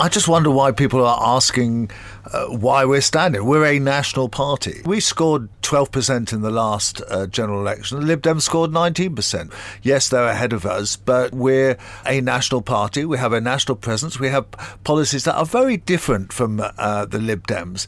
We spoke to Transport journalist Christian Wolmar who is standing on behalf of Labour in the Richmond Park by-election